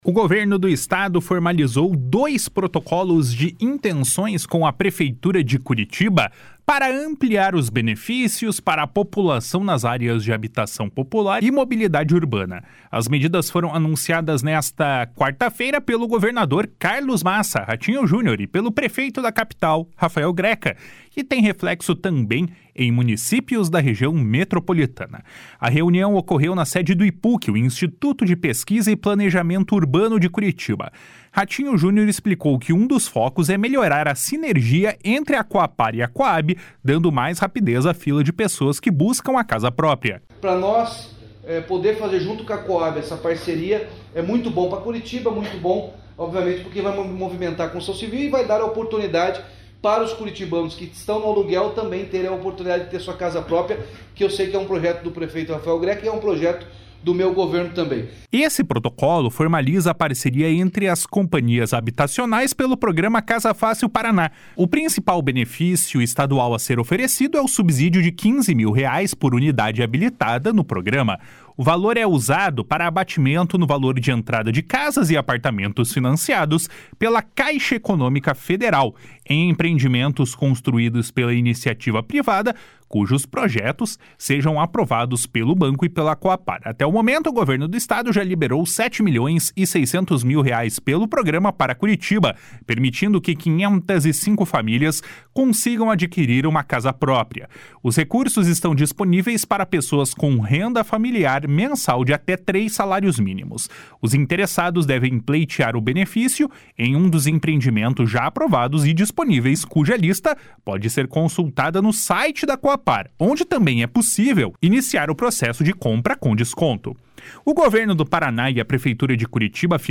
// SONORA RAFAEL GRECA //